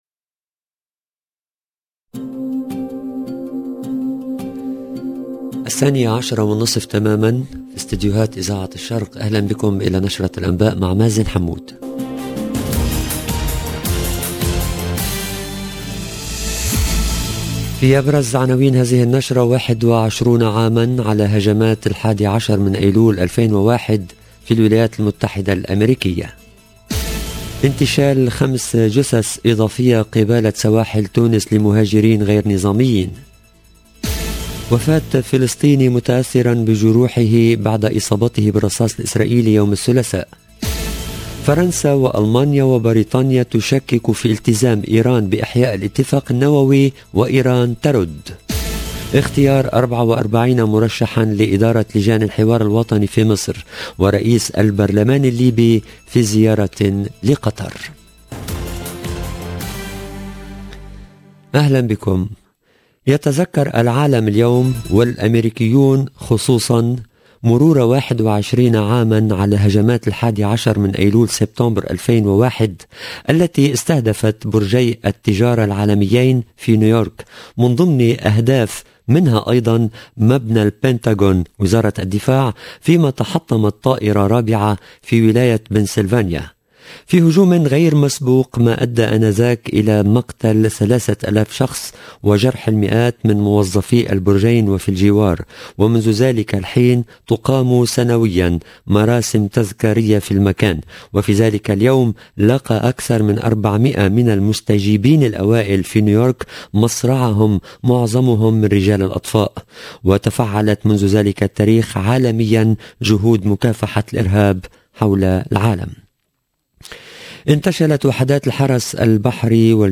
LE JOURNAL DE 12H30 EN LANGUE ARABE DU 11/9/2022
EDITION DU JOURNAL DE 12H30 EN LANGUE ARABE DU 11/9/2022